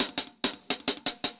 Index of /musicradar/ultimate-hihat-samples/170bpm
UHH_AcoustiHatC_170-02.wav